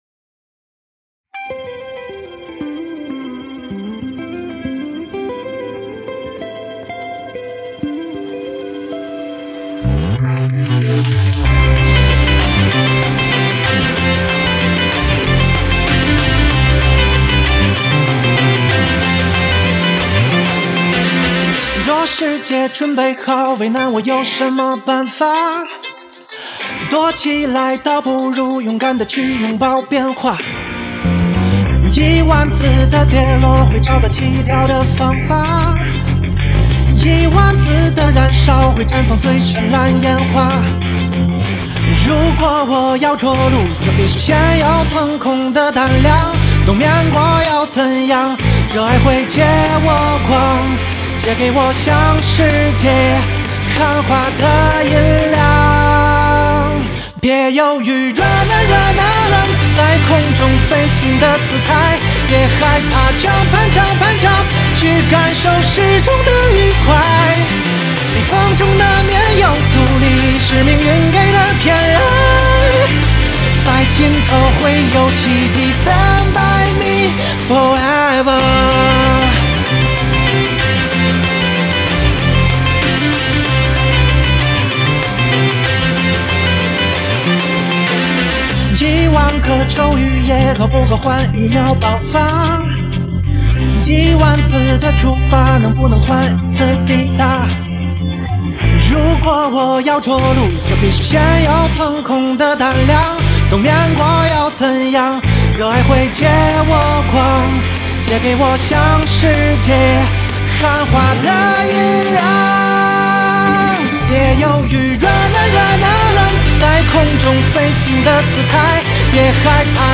乐队/组合